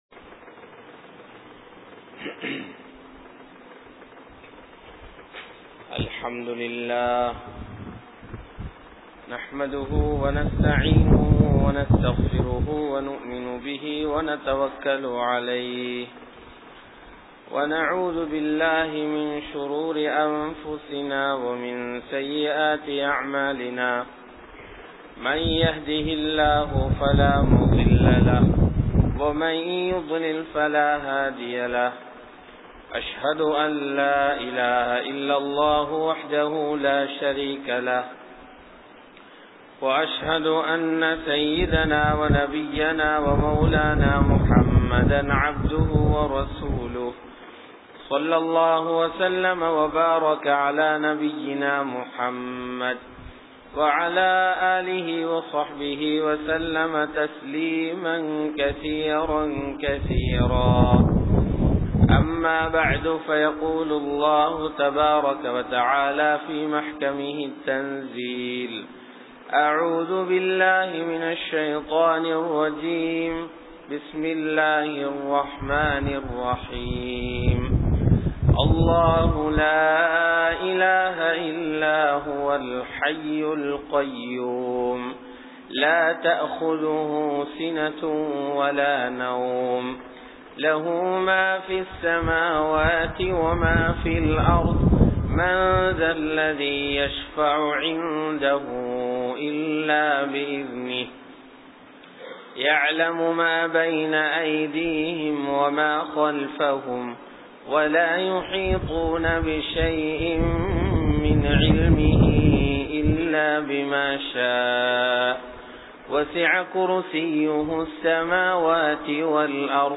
Kanampittya Masjithun Noor Jumua Masjith